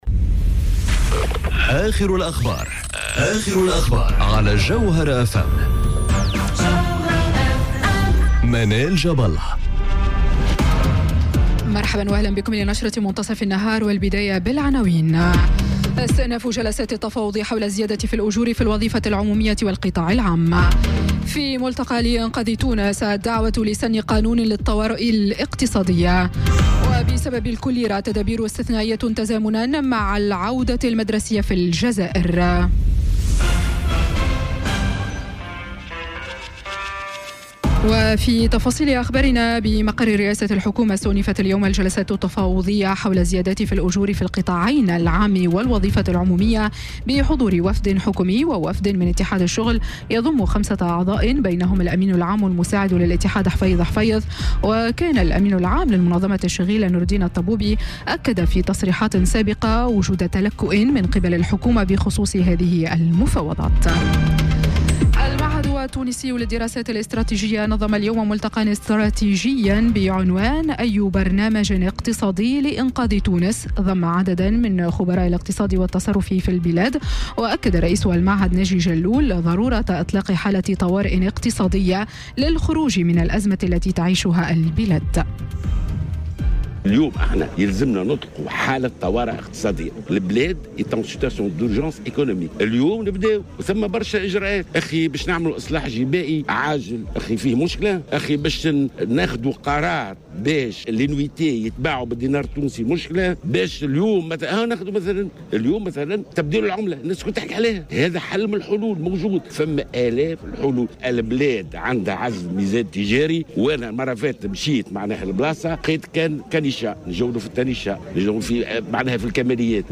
نشرة أخبار منتصف النهار ليوم الثلاثاء 28 أوت 2018